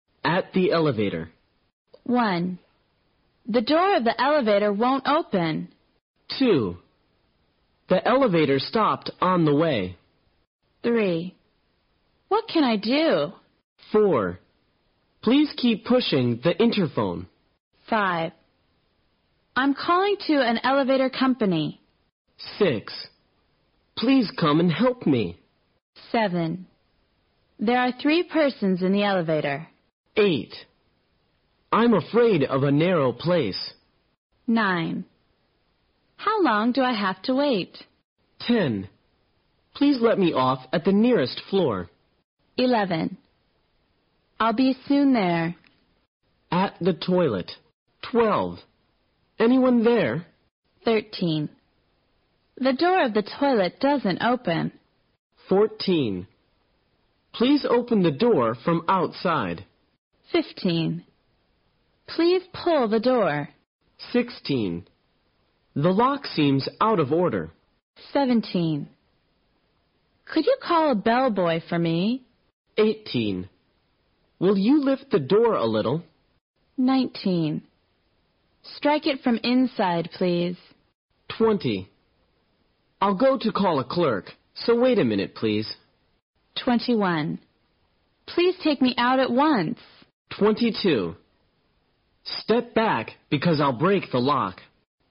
在线英语听力室随身应急英语会话 第22期:在旅馆内的紧急情况(3)的听力文件下载, 《随身应急英语会话》包含中英字幕以及地道的英语发音音频文件，是学习英语口语，练习英语听力，培养提高英语口语对话交际能力的好材料。